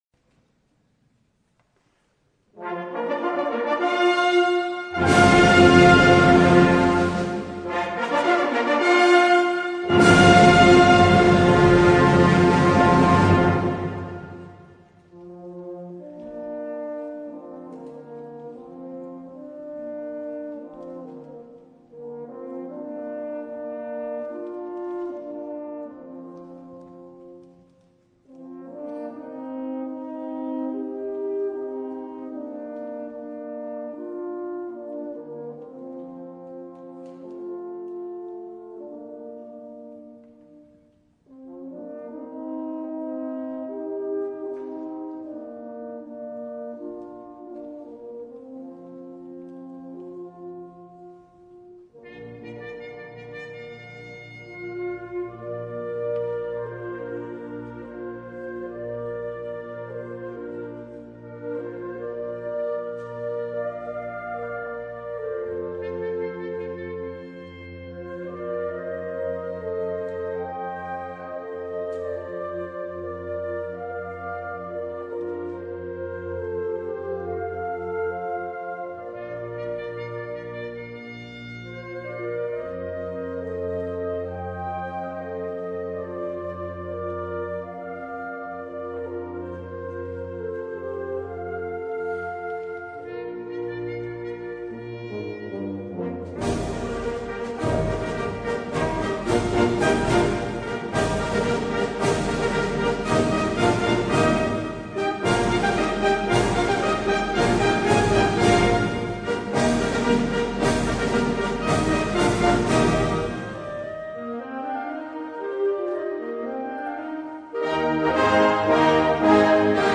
Un brano da concerto.